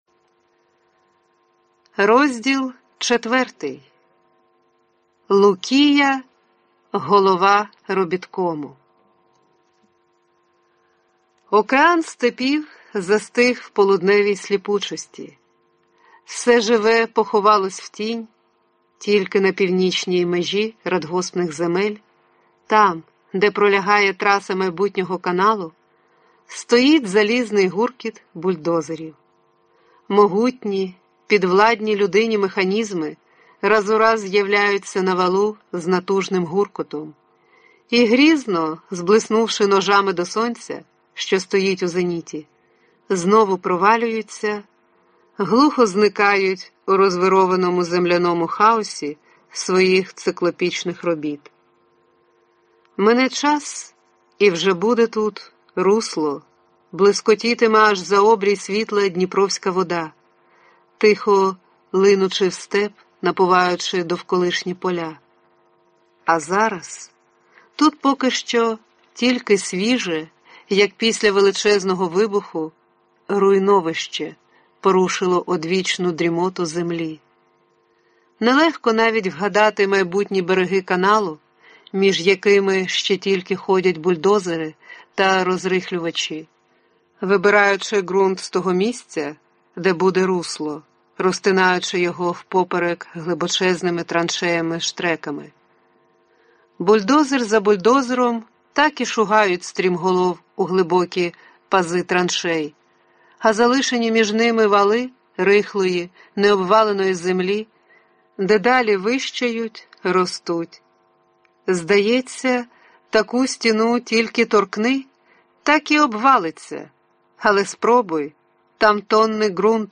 Аудиокнига Лукія Голова робіткому. Новела | Библиотека аудиокниг